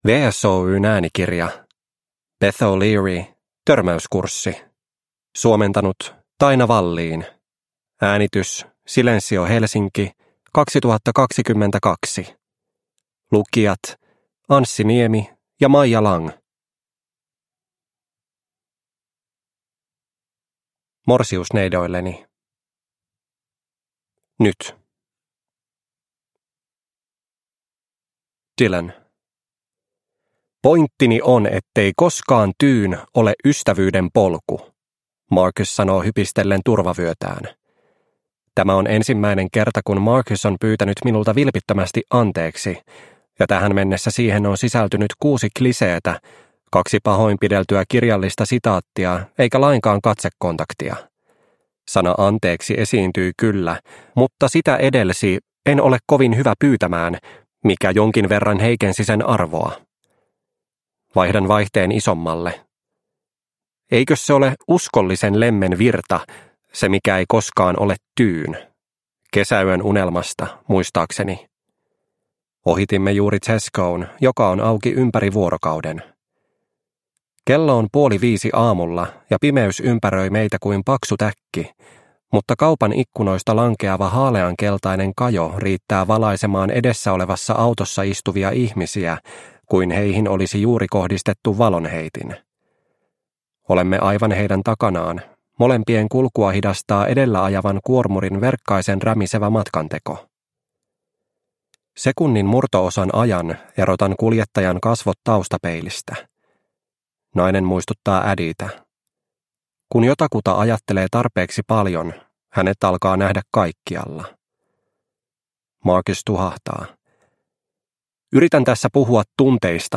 Törmäyskurssi – Ljudbok – Laddas ner